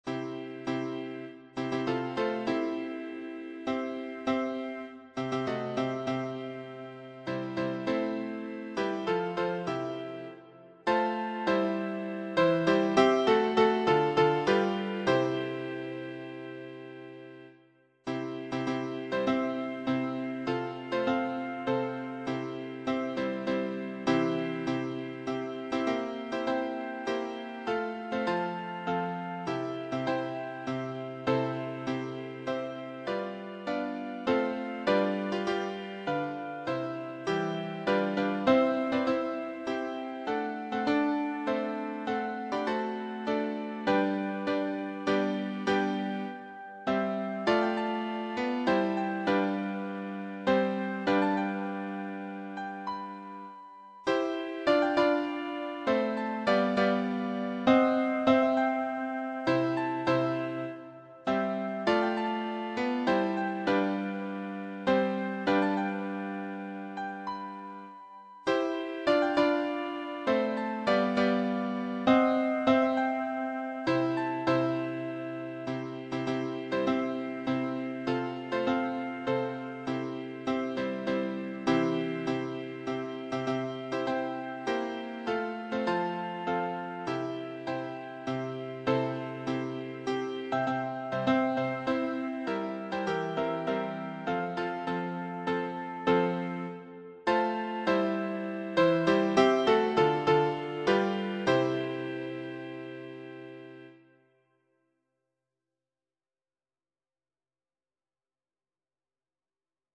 choir SAT(AT)B